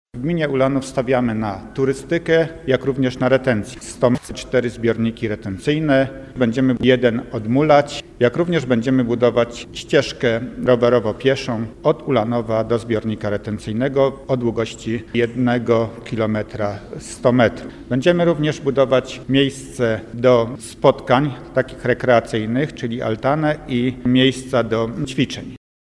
W ramach inwestycji, na którą gmina otrzymała dofinansowanie z Polskiego Ładu powstaną w sumie 4 nowe zbiorniki retencyjne. Mówi Burmistrz Ulanowa Stanisław Garbacz